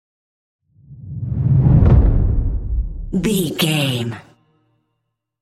Cinematic whoosh to hit deep fast
Sound Effects
Atonal
Fast
dark
intense
tension
woosh to hit